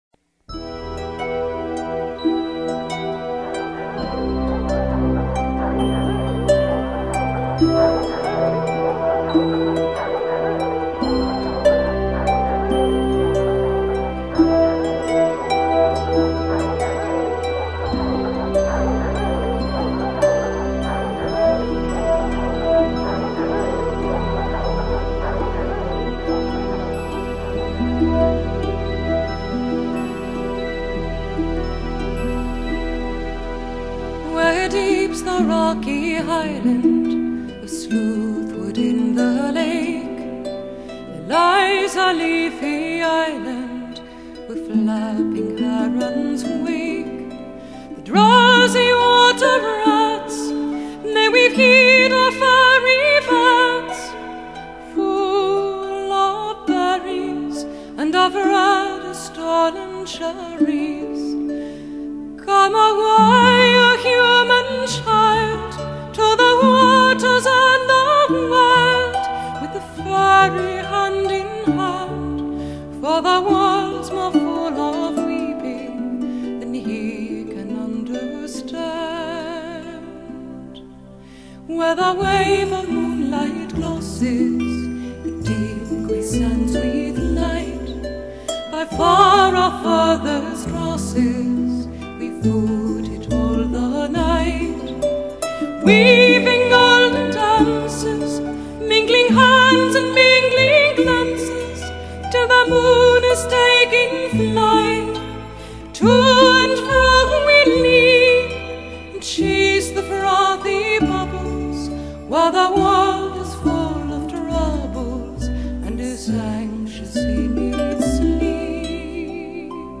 音乐类别：爵士人声
一切的一切，目的就是为了表现凯尔特音乐特有的旋律和味道。
音乐的旋律固然极其古朴而悠扬，但是歌词听来都具有爱尔兰特有的韵味。